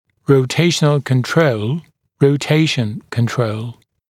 [rəu’teɪʃənl kən’trəul] [rəu’teɪʃn kən’trəul][роу’тейшэнл кэн’троул] [роу’тейшн кэн’троул]контроль ротации